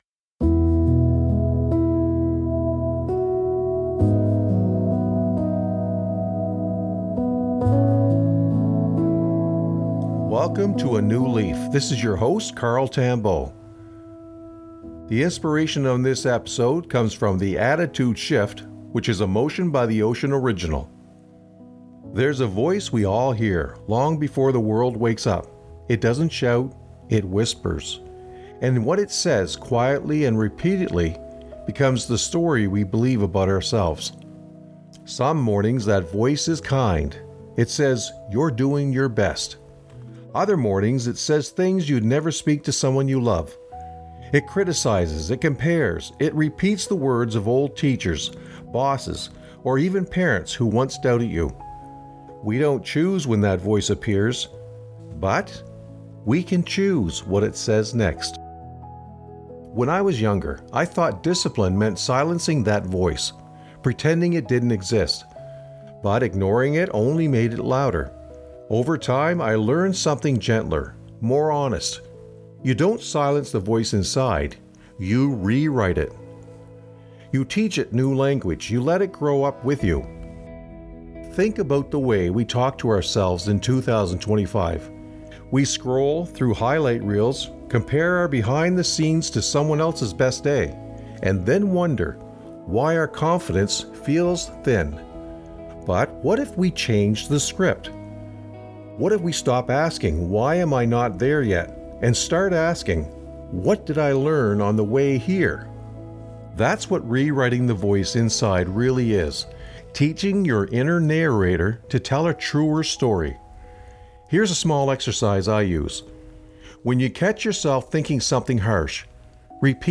Each episode feels like a breath of salt air — soothing, honest, and deeply human.